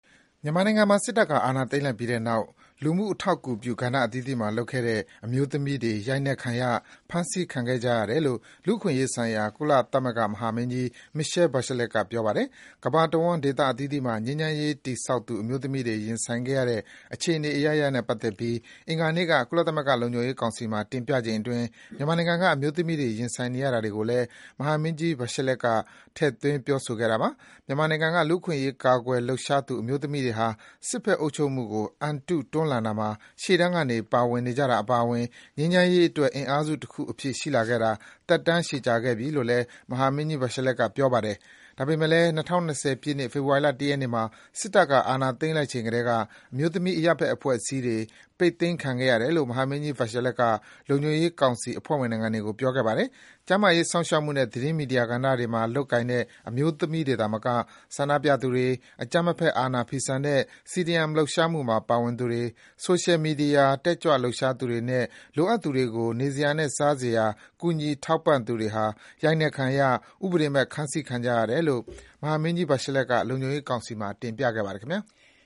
မြန်မာအမျိုးသမီးရေး ကုလမဟာမင်းကြီး လုံခြုံရေးကောင်စီမှာပြောဆို